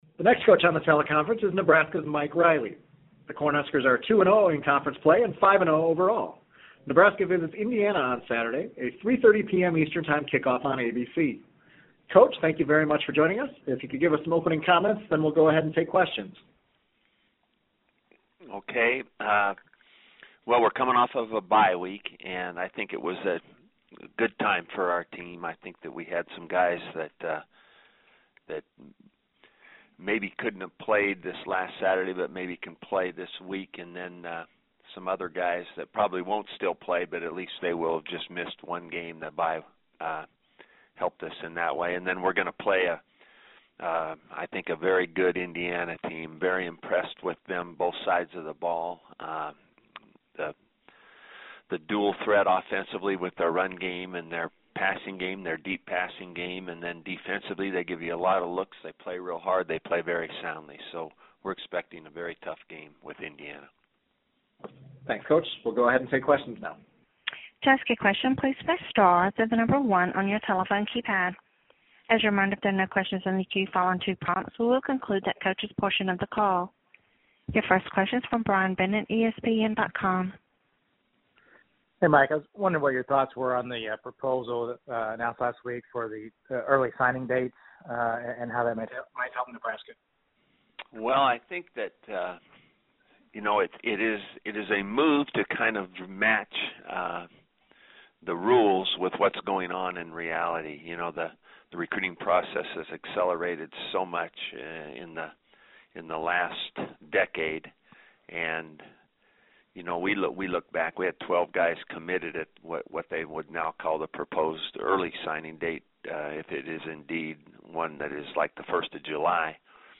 Each Tuesday morning during the Big Ten football season, the 14 Big Ten football coaches hold a Big Ten teleconference with reporters from around the country.